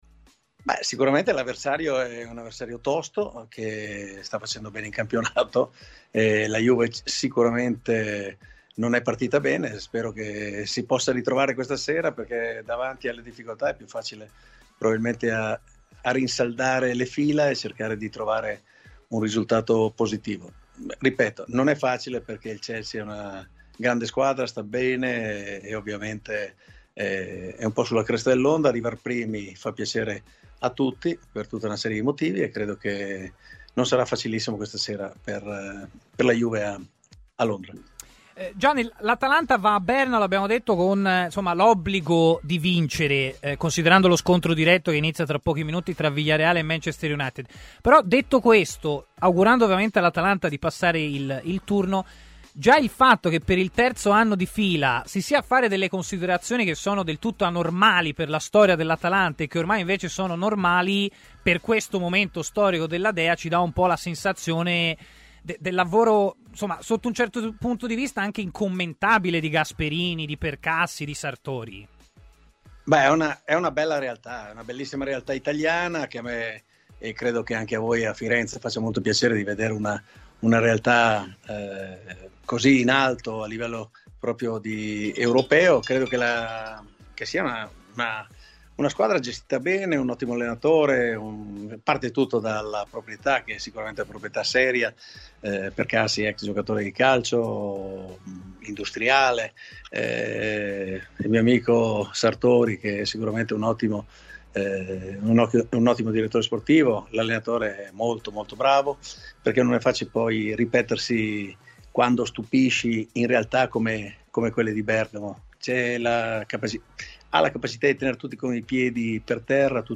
Gianni De Biasi, ct dell'Azerbaigian, ha parlato ai microfoni di Tmw Radio